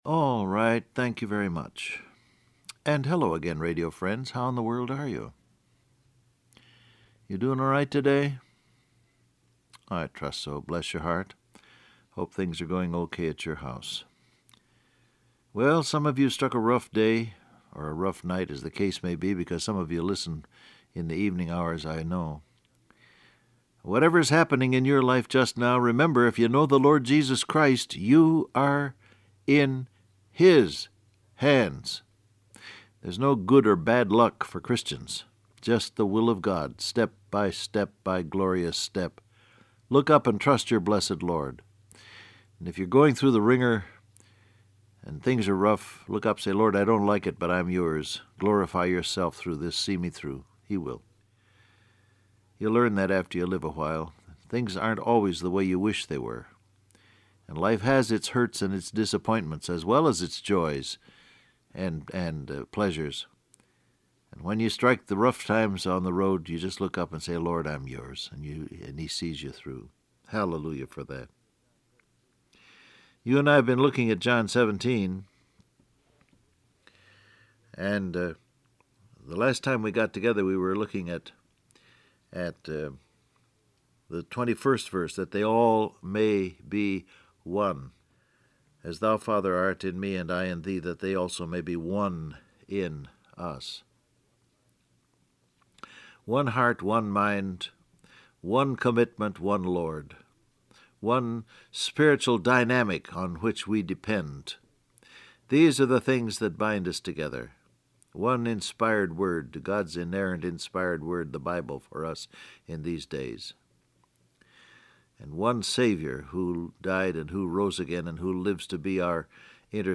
Download Audio Print Broadcast #6955 Scripture: John 17:21-22 , Acts 4:33 Topics: Believe , Presence Of God , Proof , His Glory Transcript Facebook Twitter WhatsApp Alright.